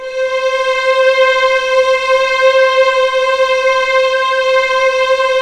WARM VIOL LM 26.wav